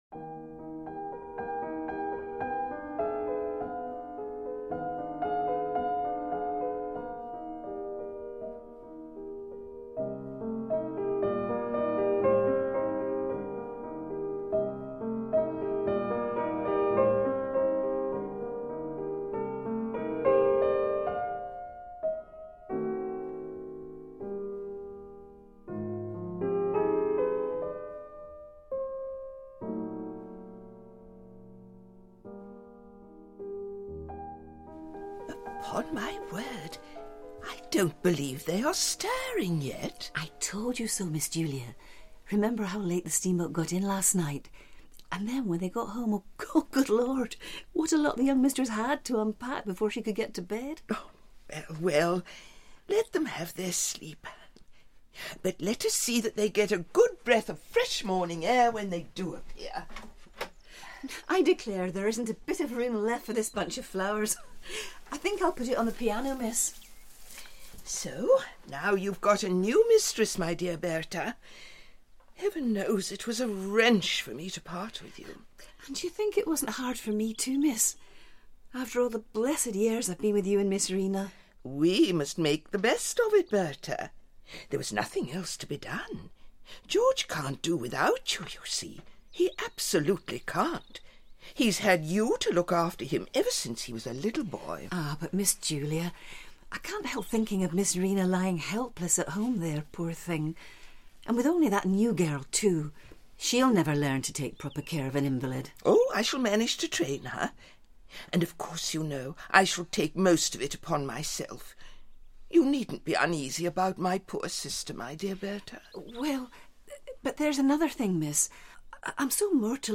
Hedda Gabler (EN) audiokniha
Ukázka z knihy